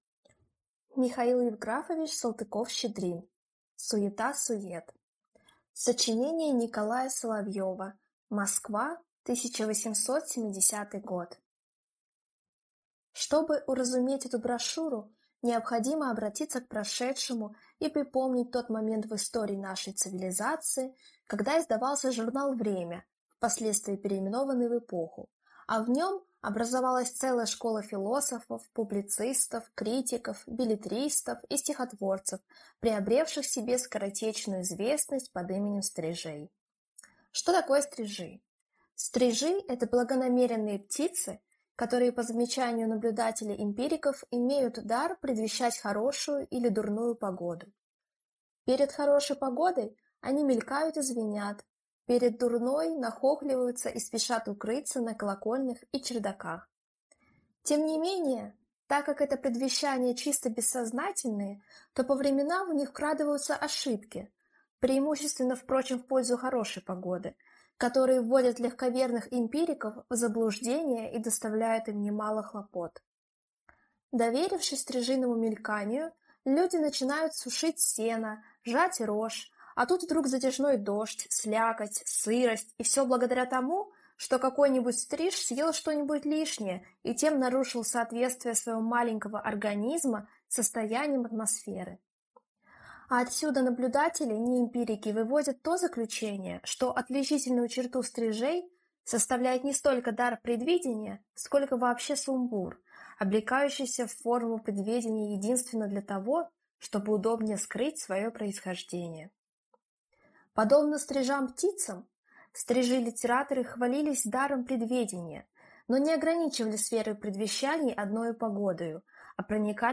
Аудиокнига Суета сует | Библиотека аудиокниг
Прослушать и бесплатно скачать фрагмент аудиокниги